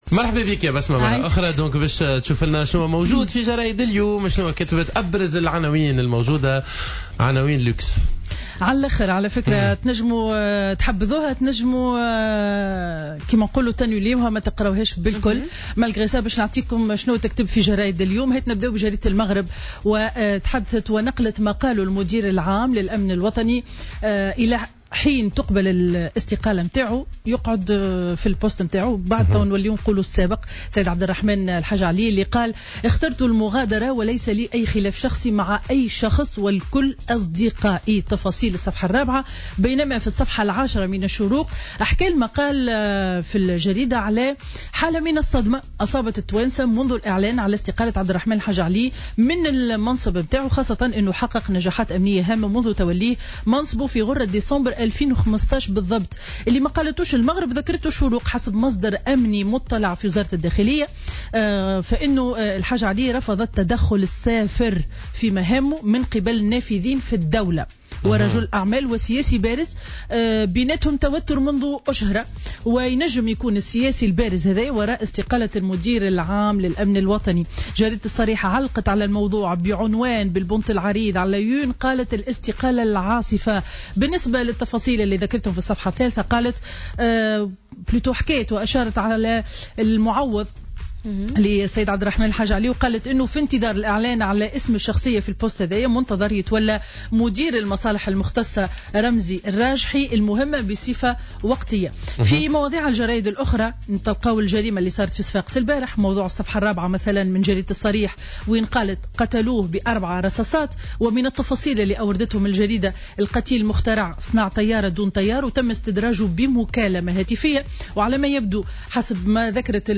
Revue de presse du vendredi 16 décembre 2016